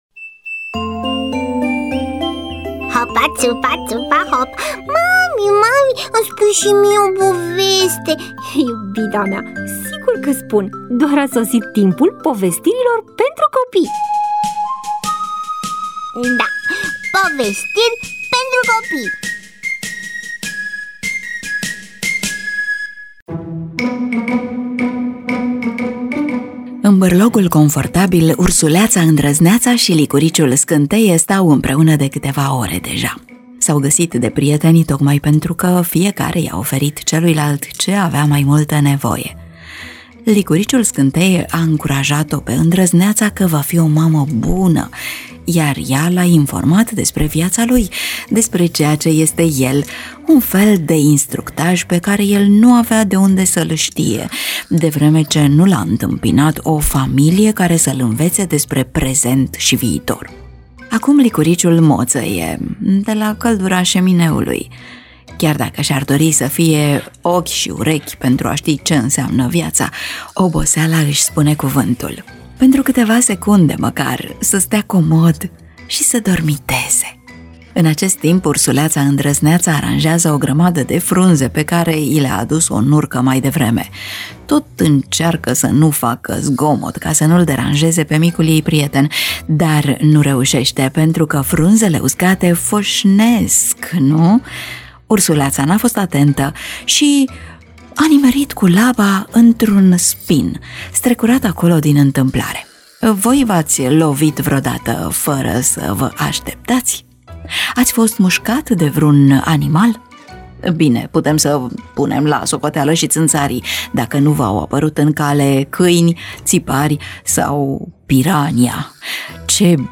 EMISIUNEA: Povestiri pentru copii